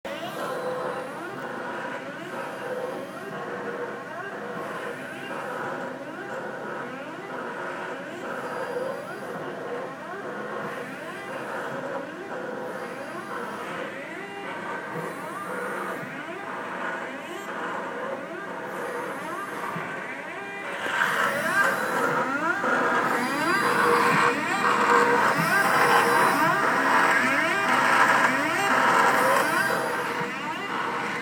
~16-Sep-2011: cleaned the roller/knife assembly (see below) because the printer was making a loud noise while printing.